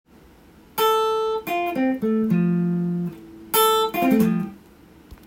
Dm7のコード上で使えるフレーズをtab譜にしてみました。
２～３本ほどの弦をさらっと弾くフレーズになります。